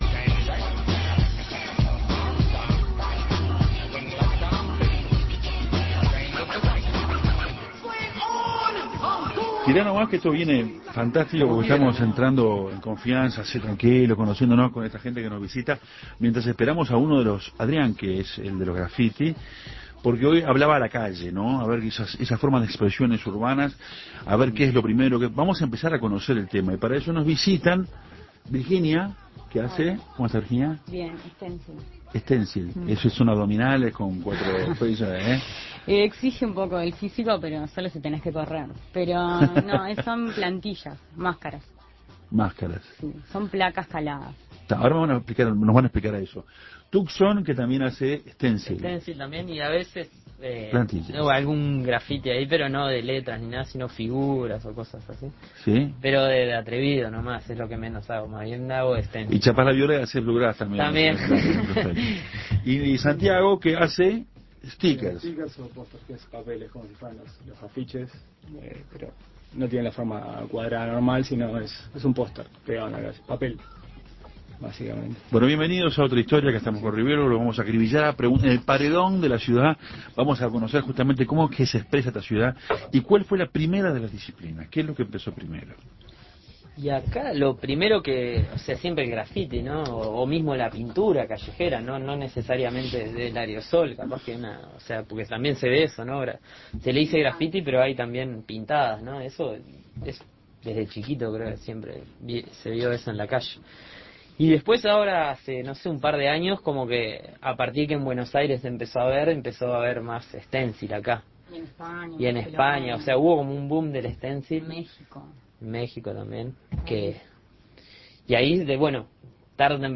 Montevideo no está excluido de este movimiento y existe un grupo de personas que se dedican a las intervenciones urbanas. En Otra Historia estuvo un grupo de personas que, como hobby y forma de superación, realizan graffiti, stickers y stencil.